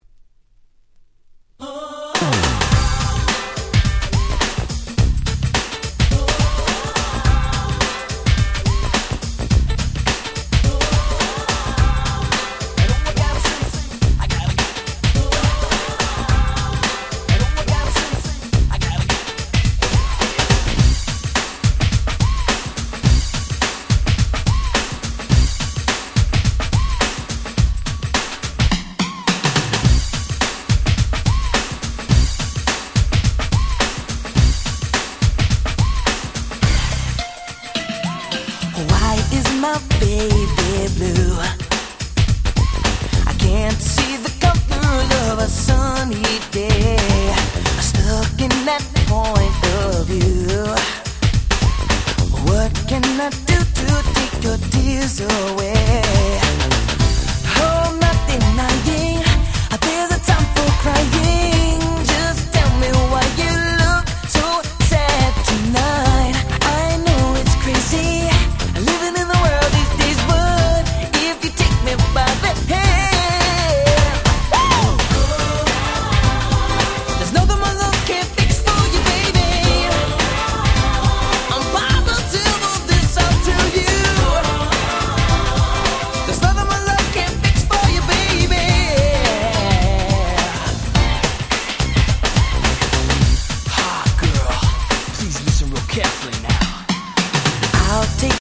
93' Smash Hit R&B / New Jack Swing !!
ハネたBeatにエモーショナルな歌声の映える超爽やかな人気曲！！
ハネ系 NJS ニュージャックスウィング キャッチー系 90's